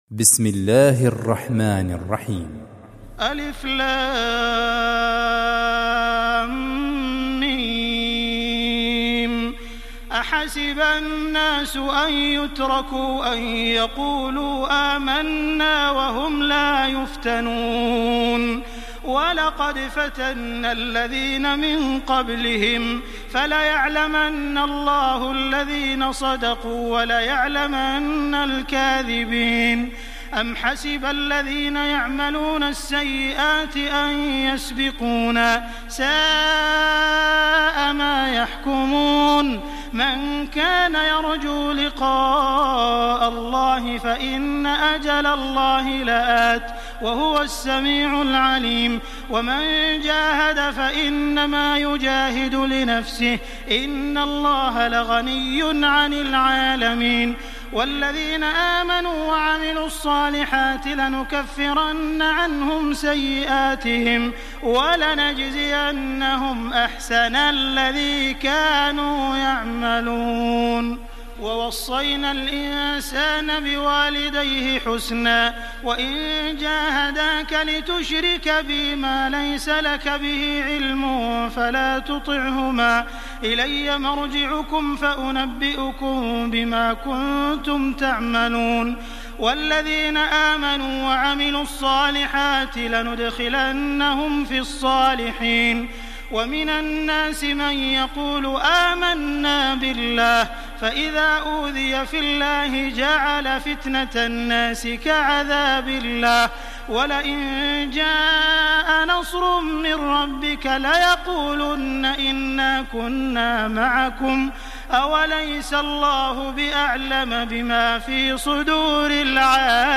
استمع الى باقي السور للقارئ عبد المحسن القاسم